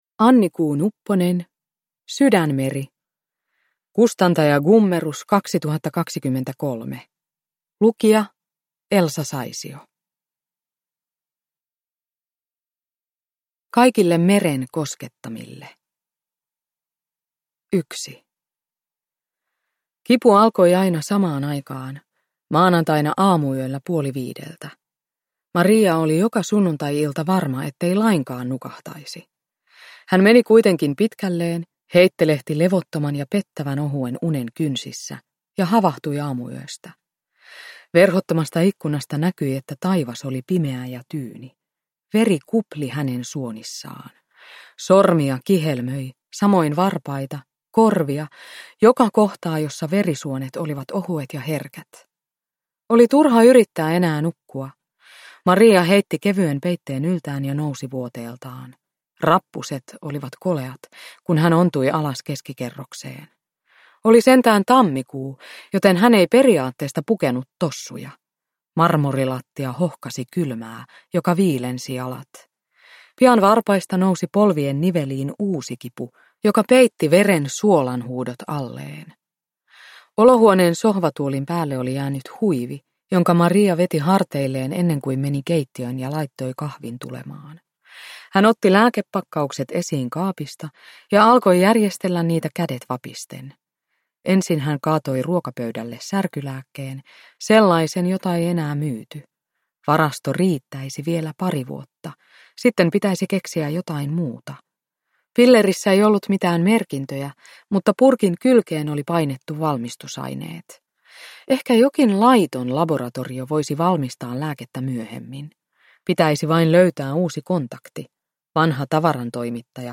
Sydänmeri – Ljudbok – Laddas ner
Uppläsare: Elsa Saisio